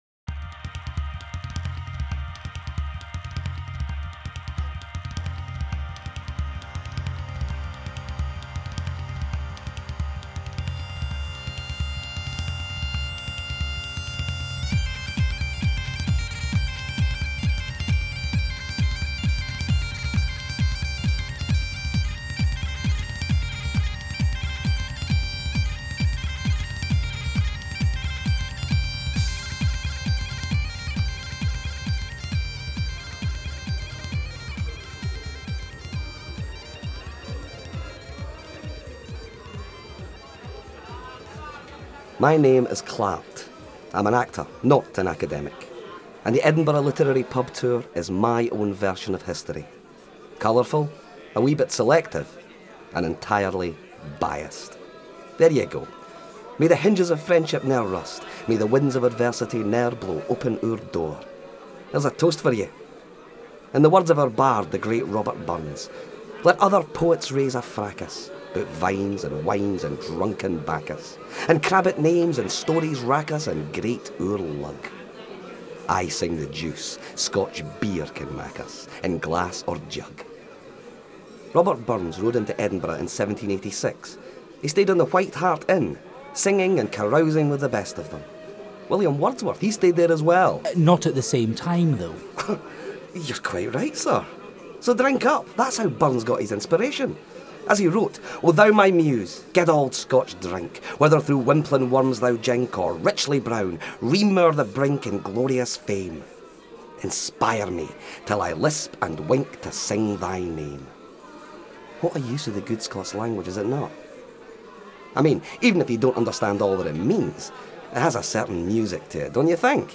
A Hilarious duel of wits across three hundred years of great writing and colourful characters. The Edinburgh Literary Pub Tour incorporates a dramatic script performed by two professional actors, not tour guides reading from books.
“The Edinburgh Literary Pub Tour”, by demand, is now available in a professionally recorded audio format.